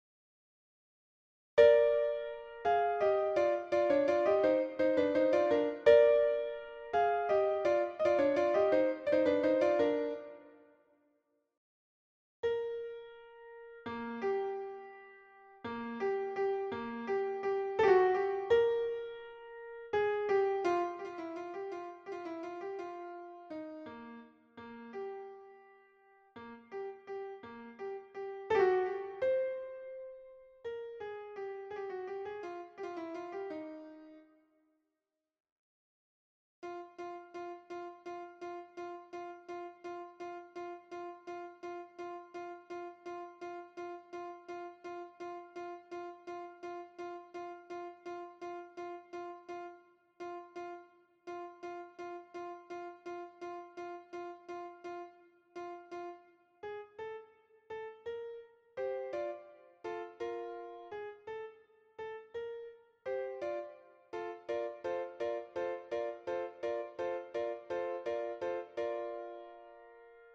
Sopranes+ Altos -
La_Traviata_SOPRANES_Libiamo_ne_lieti_calici.mp3